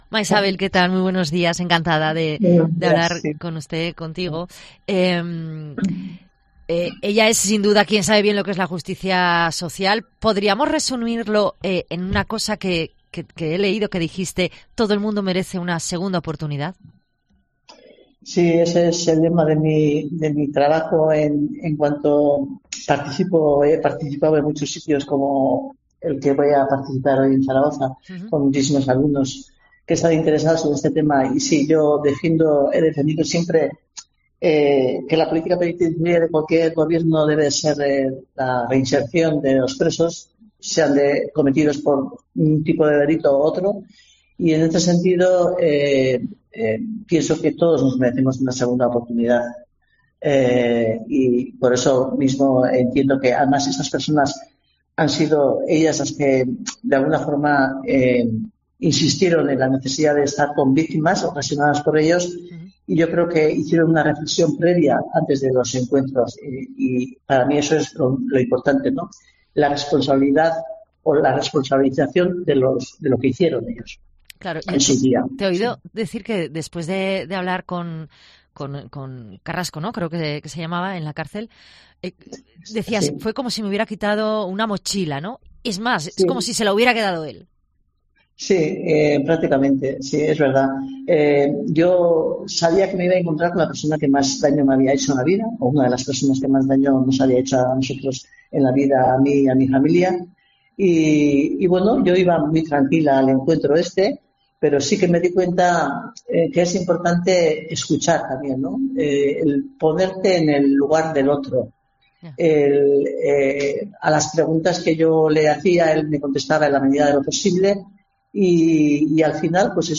Entrevista a Maixabel Lasa, viuda de Juan María Jáuregui, asesinado por ETA en el año 2.000